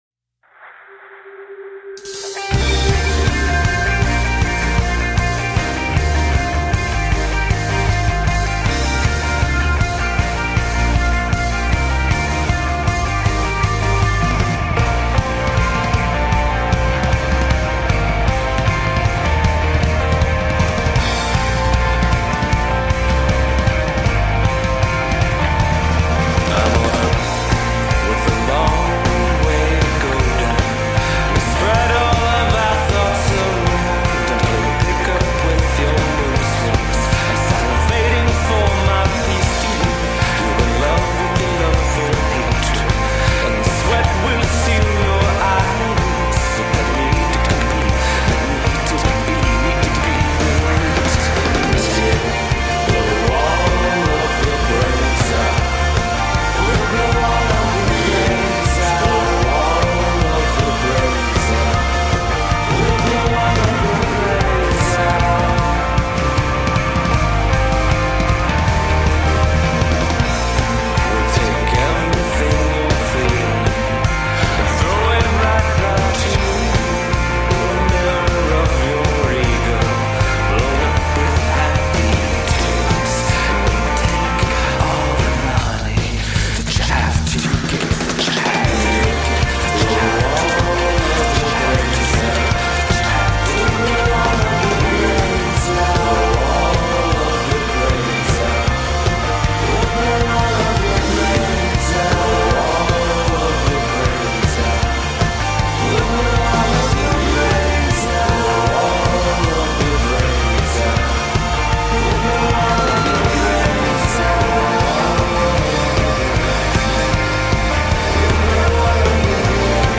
Hook-laden anthems of survival and triumph.
Tagged as: Electro Rock, Alt Rock, Darkwave, Downtempo, Goth